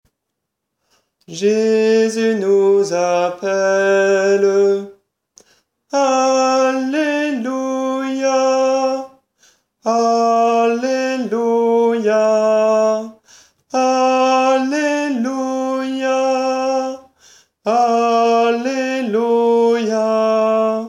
Voix chantée (MP3)COUPLET/REFRAIN
TENOR